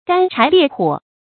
干柴烈火 注音： ㄍㄢ ㄔㄞˊ ㄌㄧㄝ ˋ ㄏㄨㄛˇ 讀音讀法： 意思解釋： 形容男女之間強烈情欲要求，多用于不正當的男女關系。